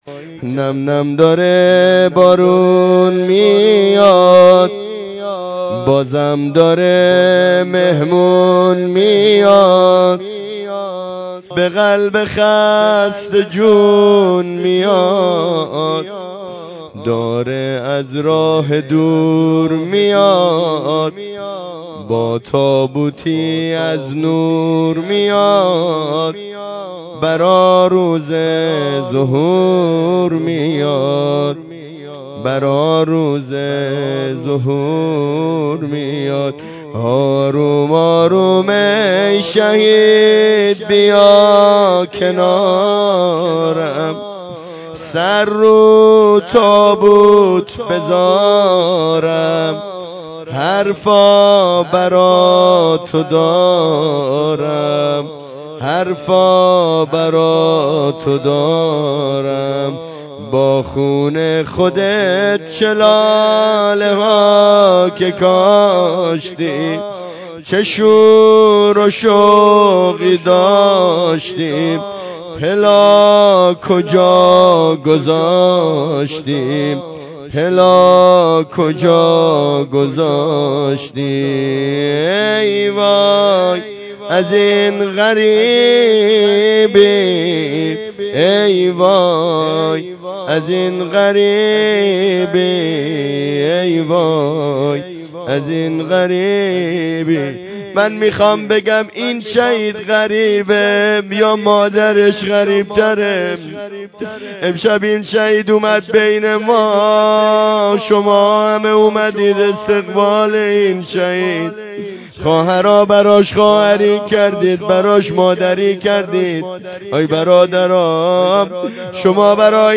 زمزمه شهدایی